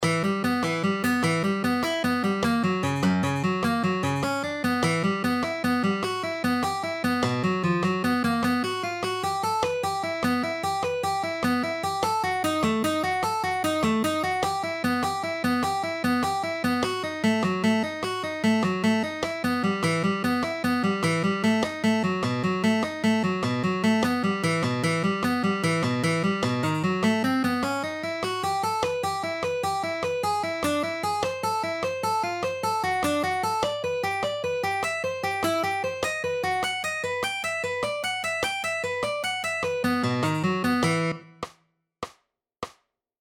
Alternate Picking Exercises 3
Alternate-Picking-Exercises-3-3.mp3